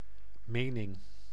Ääntäminen
IPA: /ˈmeːnɪŋ/